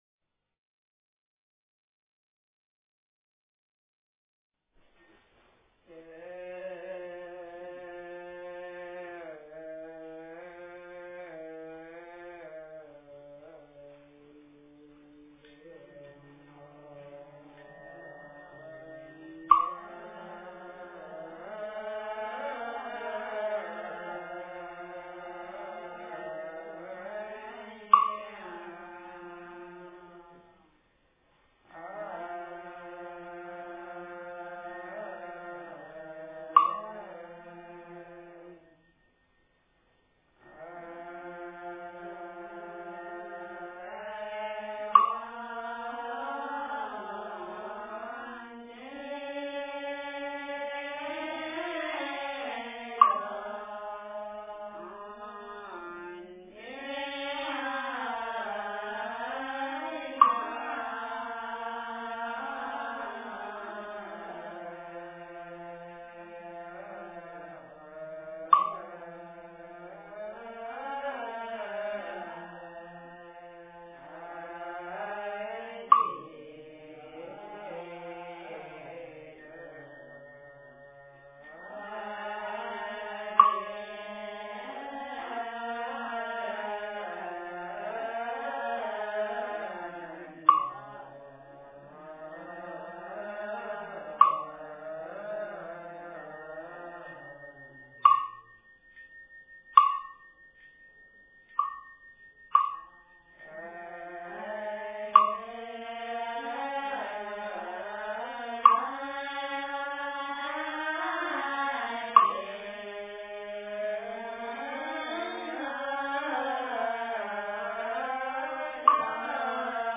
标签: 佛音经忏佛教音乐